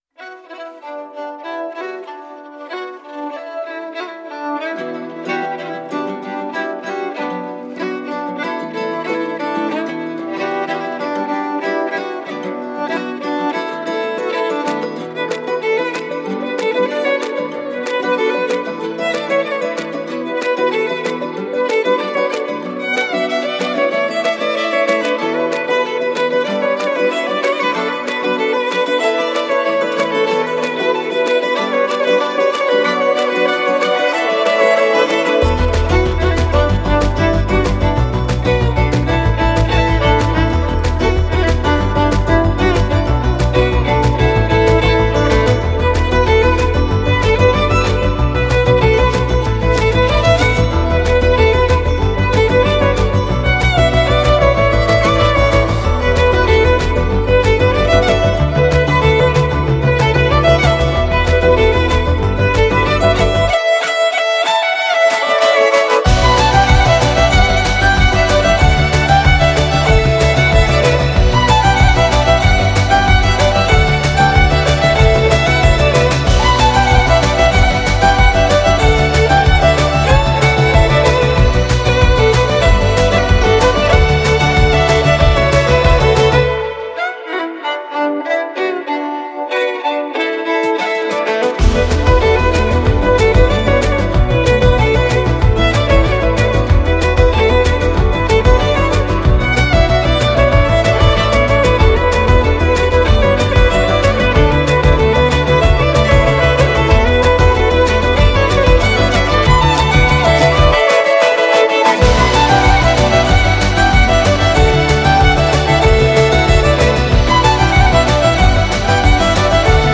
Instrumental
موسیقی ویولن violin